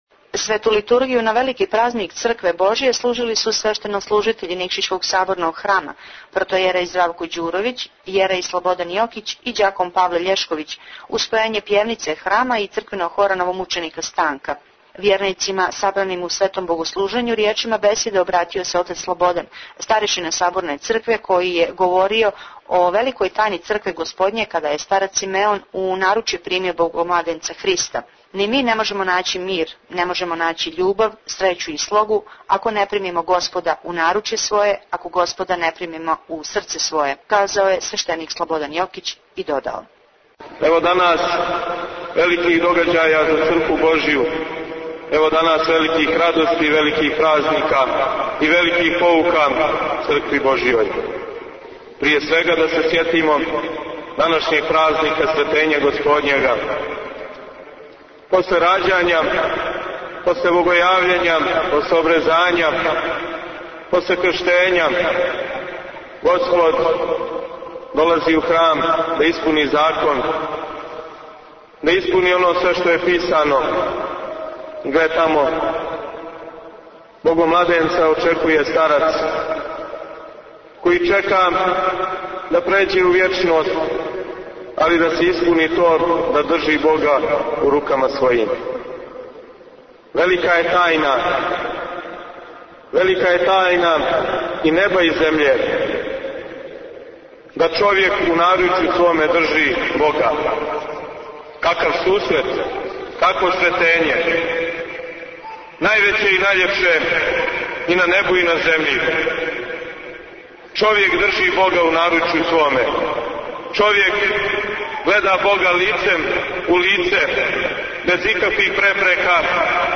Празник Сретење Господње молитвено прослављен у Никшићу Tagged: Извјештаји 8:06 минута (1.39 МБ) Празник Сретење Господње прослављен молитвено у Саборној цркви Светог Василија Острошког у Никшићу.